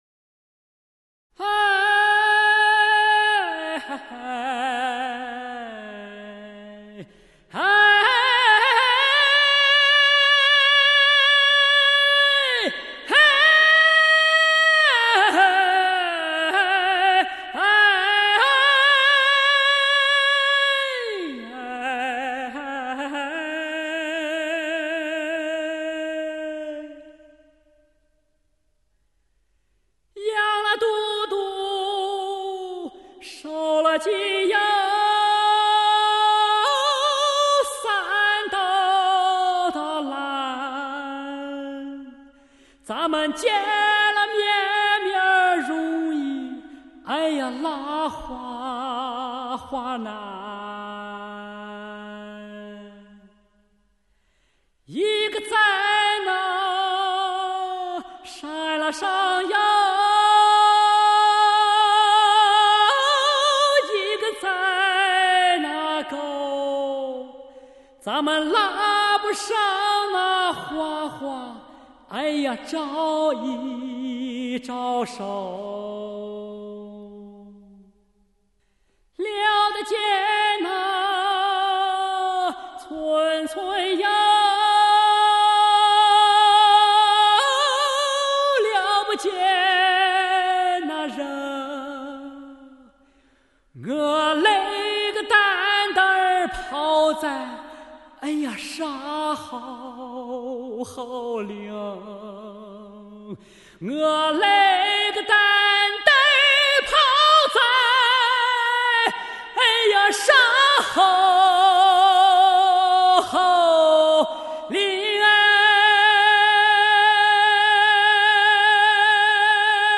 气势庞大绵延，声音清晰饱满，音质圆润亮丽。